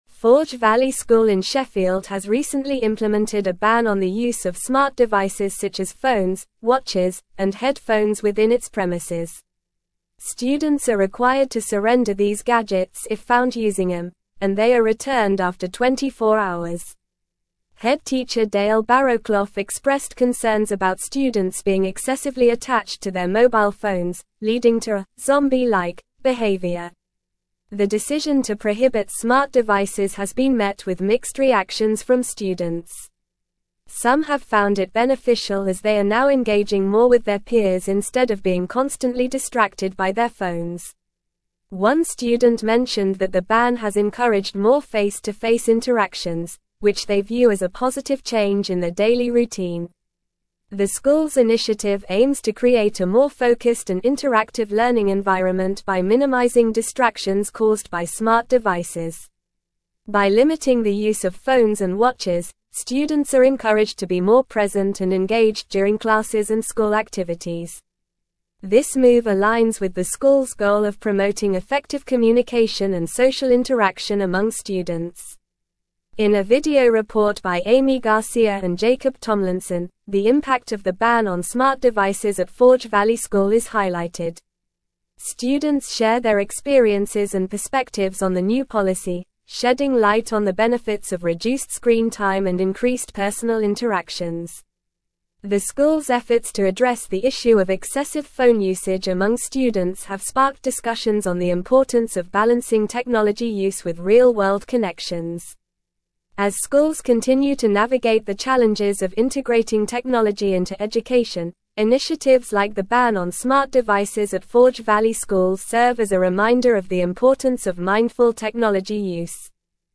Normal
English-Newsroom-Advanced-NORMAL-Reading-Forge-Valley-School-Implements-Ban-on-Smart-Devices.mp3